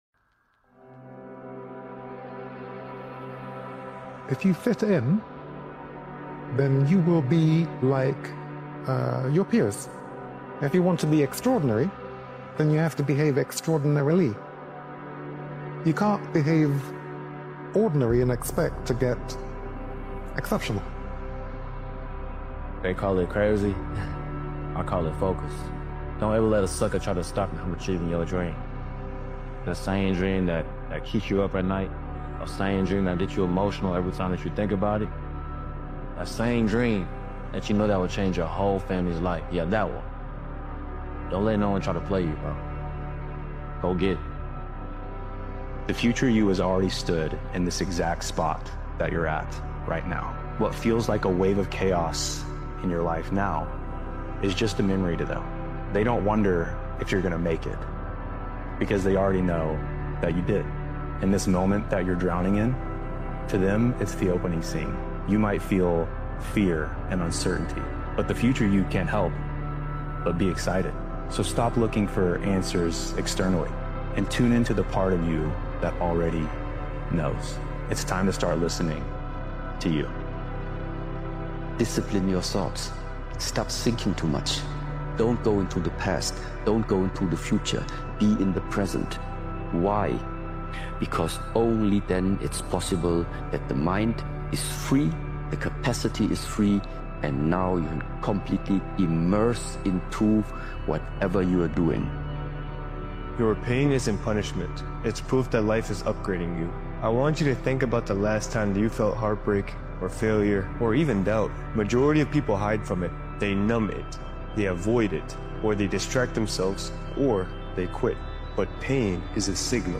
Step into a world of inspiration where motivational speeches, empowering messages, and transformative self-improvement strategies await you.